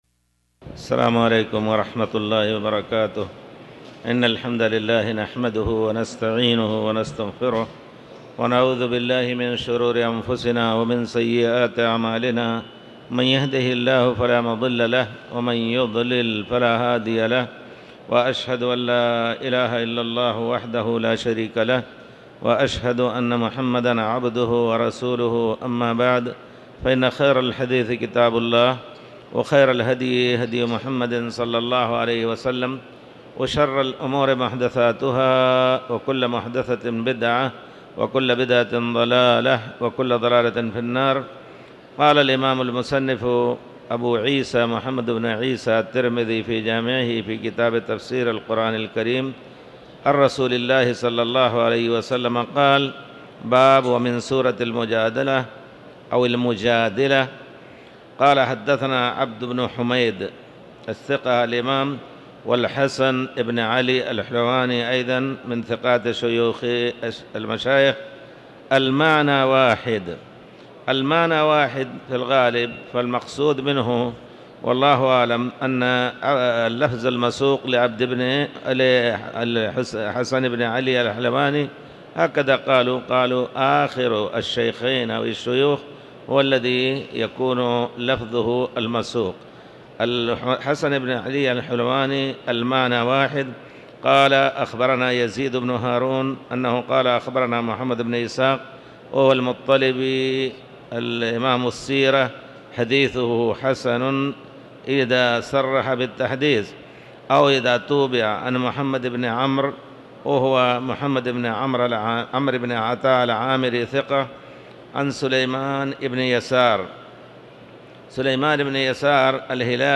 تاريخ النشر ٢٧ ربيع الثاني ١٤٤٠ هـ المكان: المسجد الحرام الشيخ